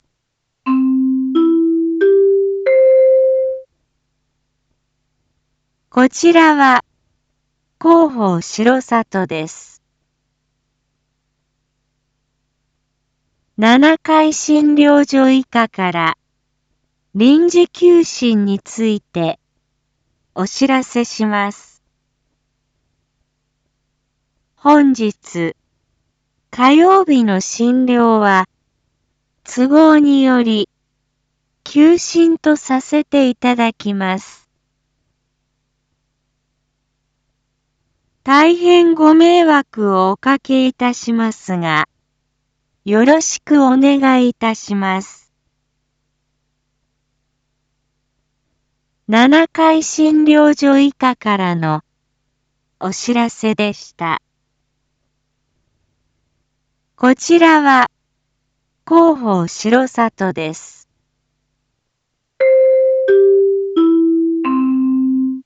一般放送情報
Back Home 一般放送情報 音声放送 再生 一般放送情報 登録日時：2024-08-13 07:01:07 タイトル：七会診療所医科臨時休診のお知らせ インフォメーション：こちらは広報しろさとです。